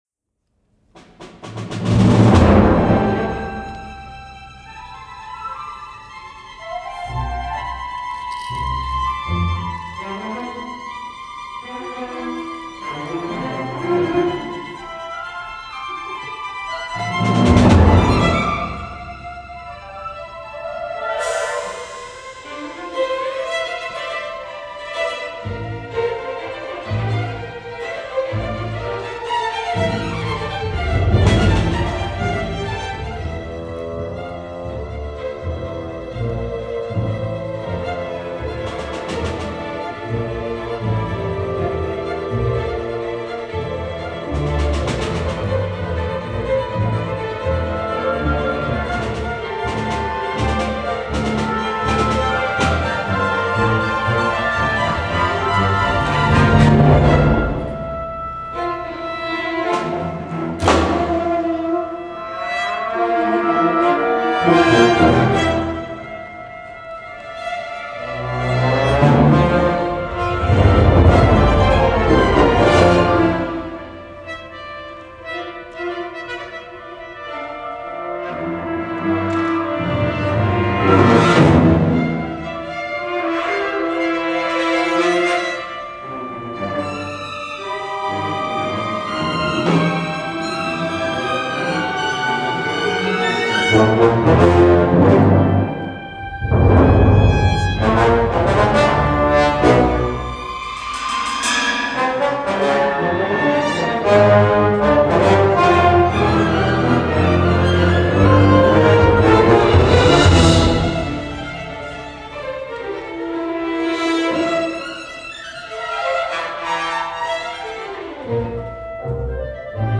LIVE RECORDING
Oboe 1, 2
Clarinet 1, 2
Tuba
Timpani